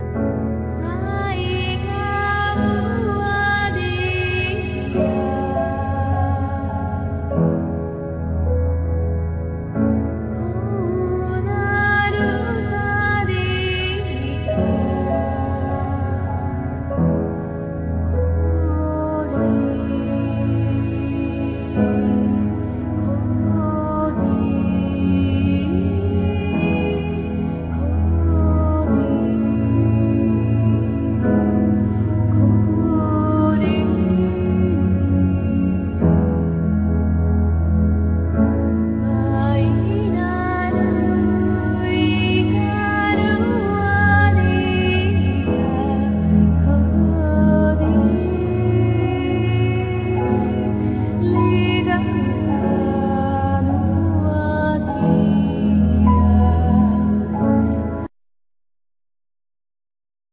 Guitar,Clarinett,Voice
Japanese orientalism